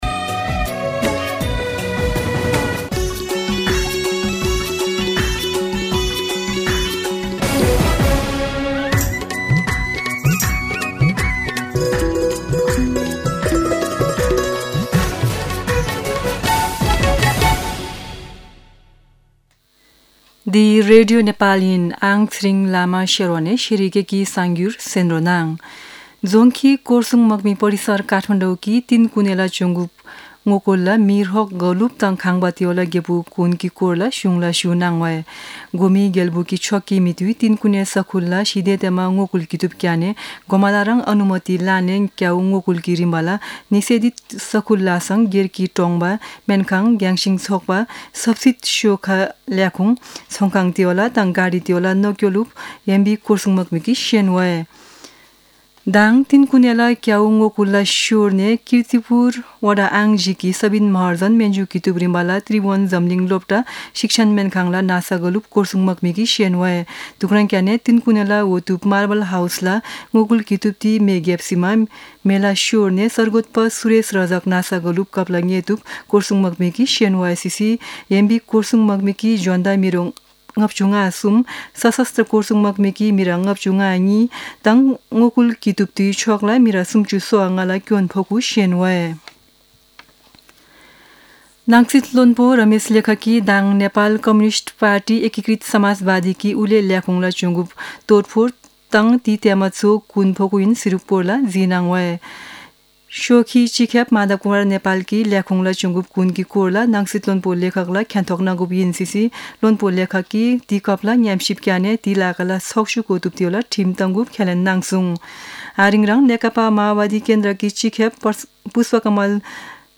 शेर्पा भाषाको समाचार : १६ चैत , २०८१
Sherpa-News12-16.mp3